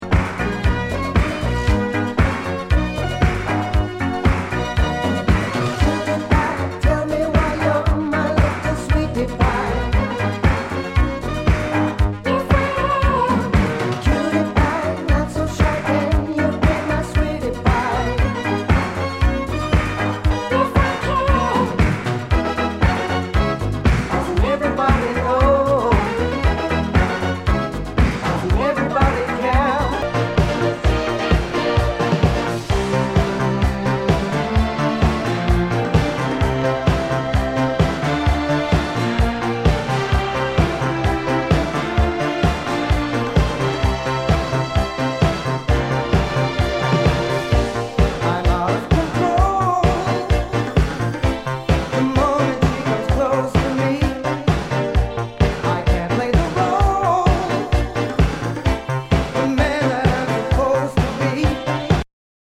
SOUL/FUNK/DISCO
ナイス！シンセ・ポップ・ディスコ / ハイエナジー！
全体にチリノイズが入ります